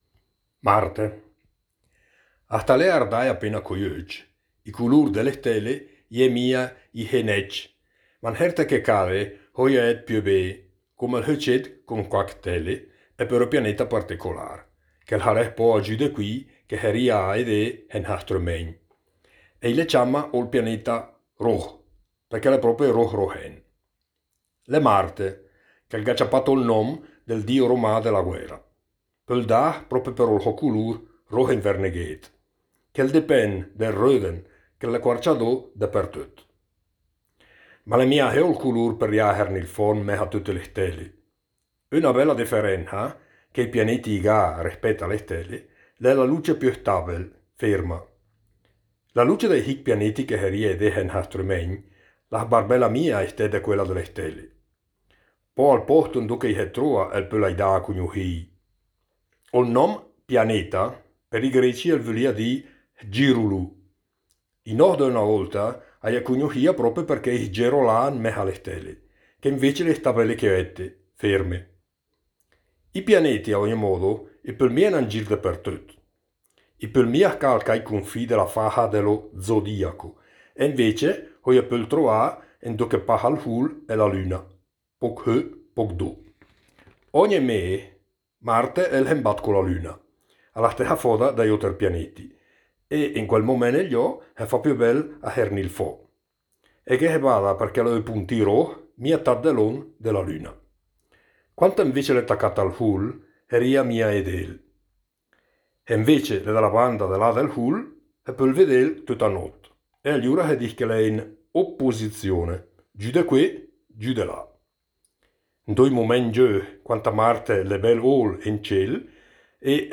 17 – Marte l’è prope roh rohèn (dialetto lumezzanese)
Marte-dialetto-lumezzanese.mp3